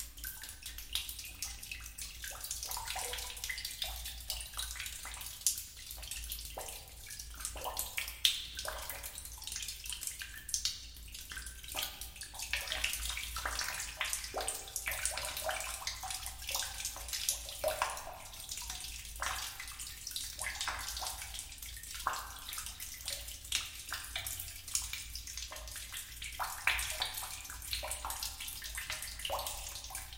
Cave_Drips_05_30_Loop.ogg